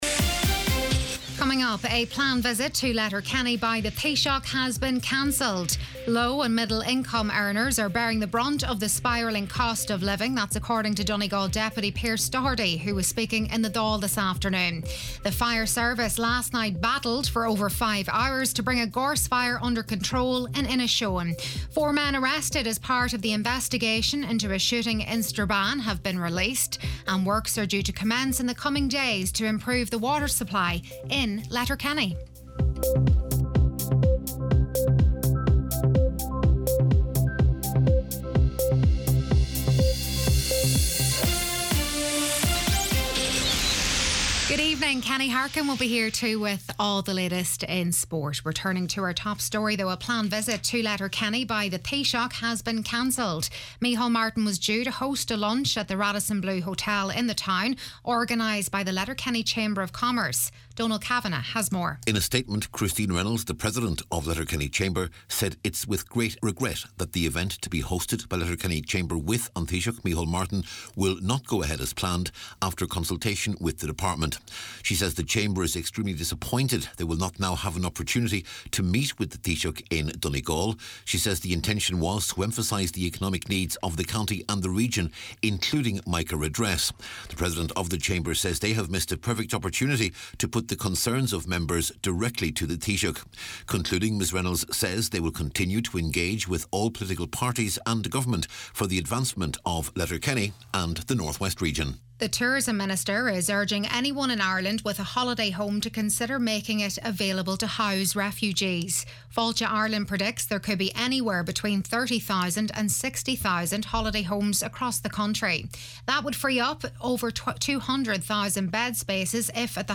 Listen back to main evening news, sport, farming news & obituaries